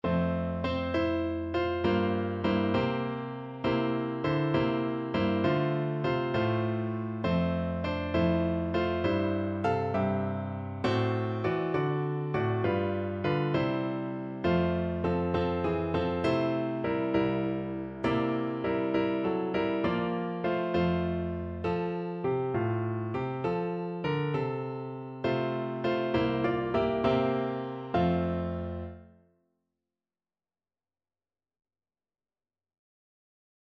Free Sheet music for Piano Four Hands (Piano Duet)
F major (Sounding Pitch) (View more F major Music for Piano Duet )
6/8 (View more 6/8 Music)
Christmas (View more Christmas Piano Duet Music)